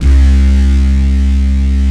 DISTBASSC2-L.wav